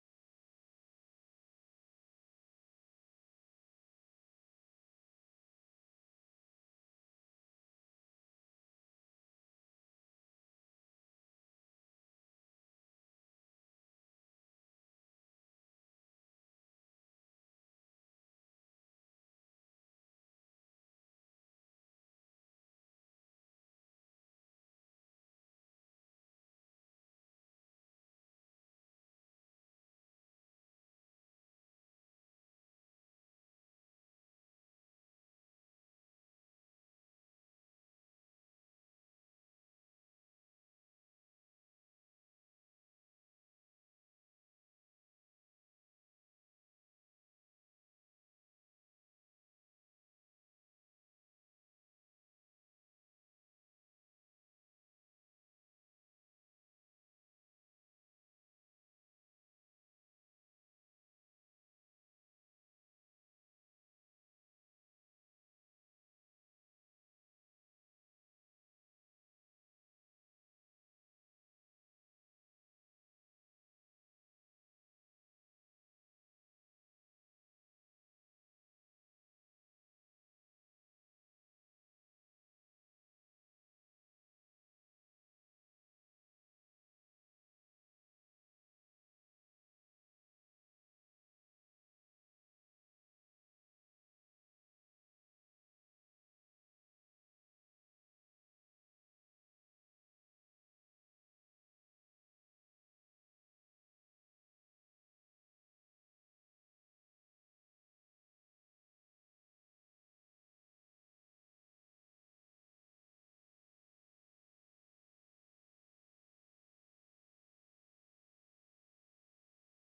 "Po chrześcijańsku" to rozmowy o filmach, książkach, muzyce i kulturze z perspektywy chrześcijańskiej. Program jest na żywo, więc można pisać, komentować i nawet zadzwonić.